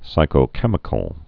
(sīkō-kĕmĭ-kəl)